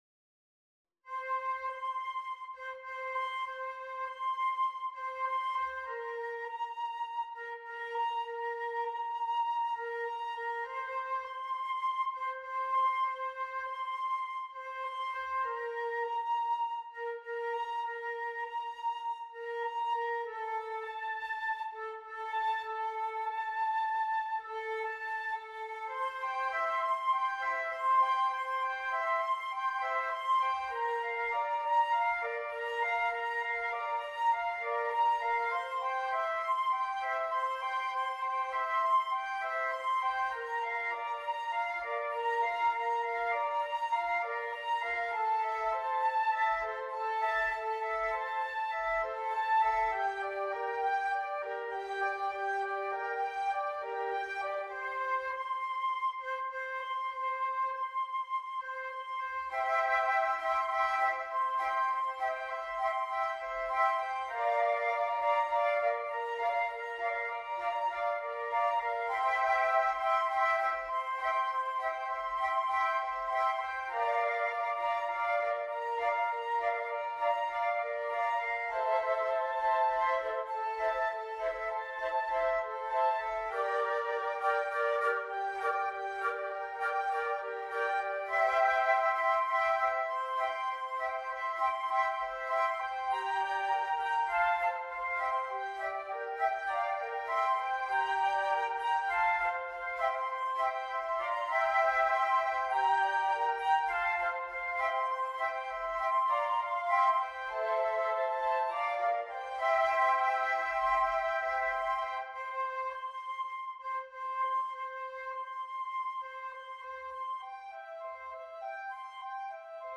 flute quartet